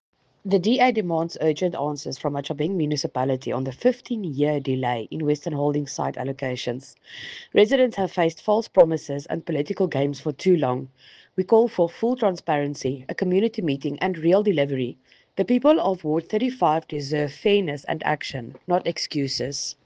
Afrikaans soundbites by Cllr Estelle Dansey and